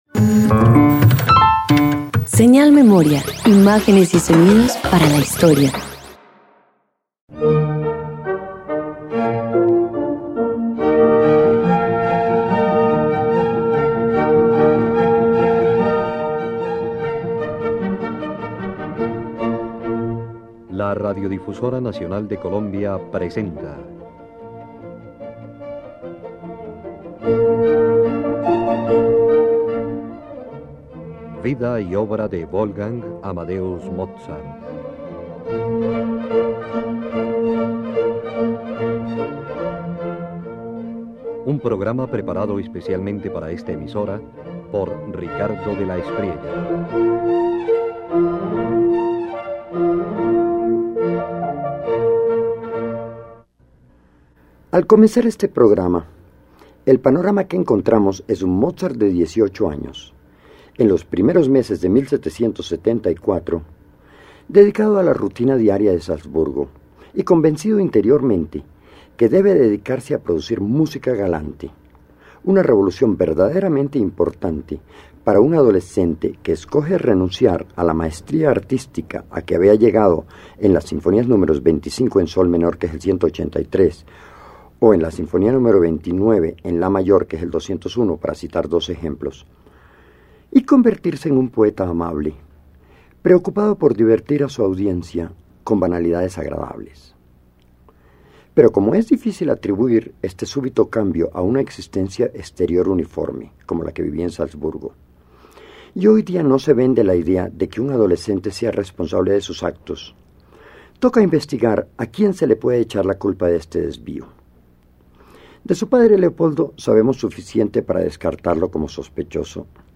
Con apenas 18 años, Mozart inicia su etapa galante. La Sonata en si bemol para piano a cuatro manos muestra la búsqueda de un estilo más ligero y social, mientras las Letanías loretanas muestran equilibrio entre devoción y brillantez orquestal.
093 Sonata  en Si Bemol  Mayor piano a 4 manos y Letanías Loretanas_1.mp3